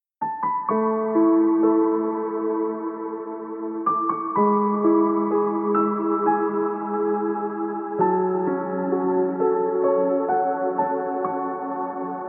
I lowered hammer noise, but that still does not sound soft as yours. Mine is too heavy. Please see the attachment, the first one is Valhalla Room, other is Valhalla Shimmer.